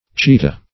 Cheetah \Chee"tah\, n. [Hind. ch[imac]t[=a].]